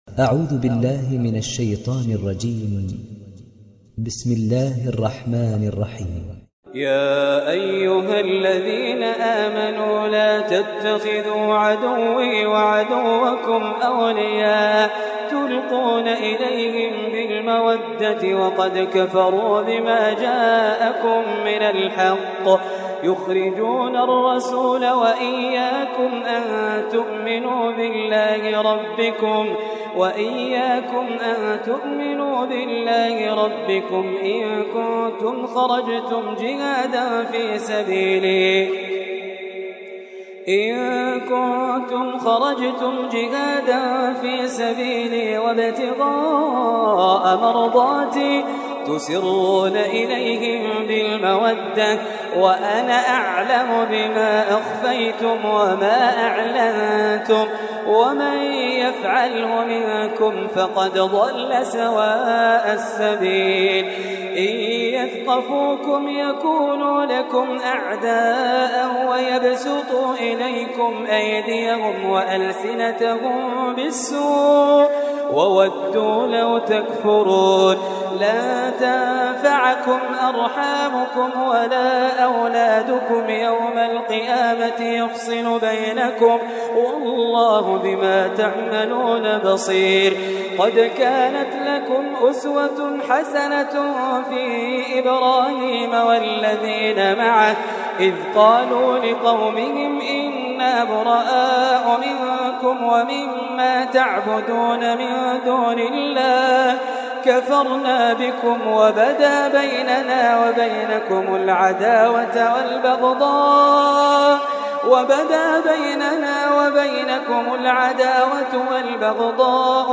Sourate Al Mumtahinah Télécharger mp3 Khalid Jaleel Riwayat Hafs an Assim, Téléchargez le Coran et écoutez les liens directs complets mp3